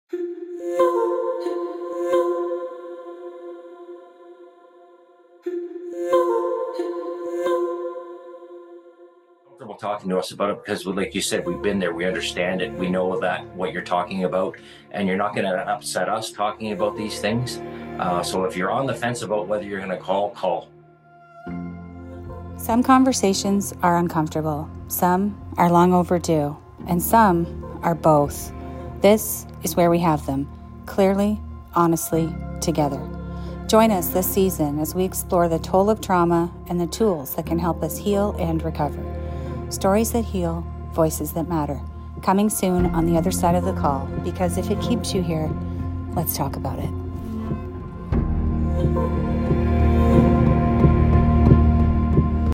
Trailer: The Other Side Of The Call - CFRC Podcast Network